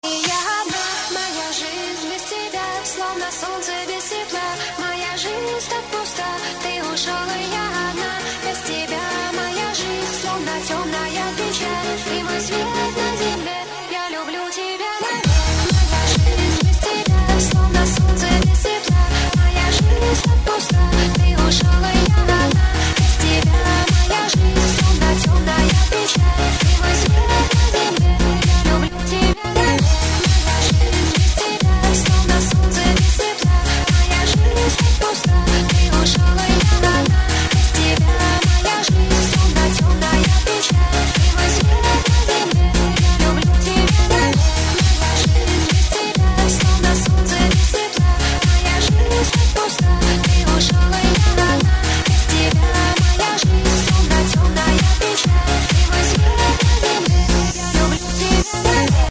• Качество: 128, Stereo
громкие
женский вокал
dance
Electronic
Club House
электронная музыка